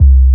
06BASS01  -R.wav